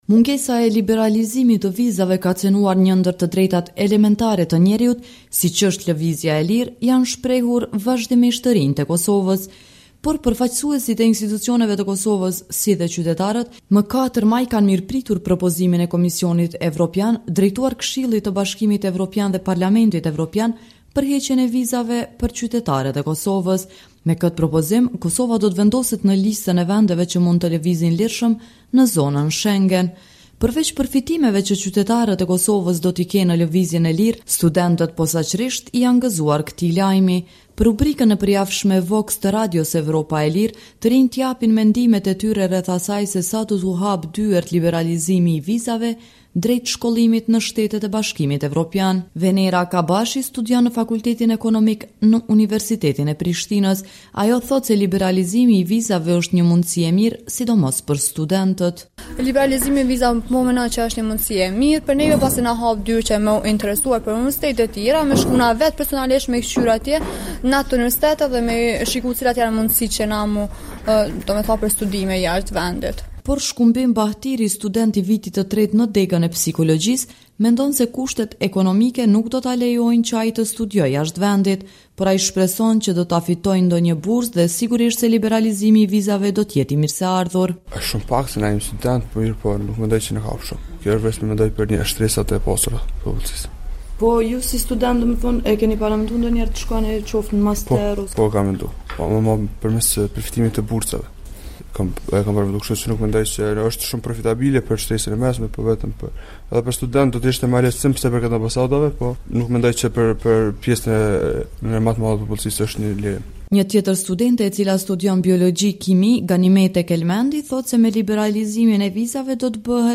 Për rubrikën e përjavshme VOX të Radios Evropa e Lirë, të rinjtë japin mendimet e tyre lidhur me rëndësinë e liberalizimit të vizave, dhe sa do t’u ofrojë lehtësira lëvizja e lirë në aspektin e shkollimit në Shtetet e Bashkimit Evropian.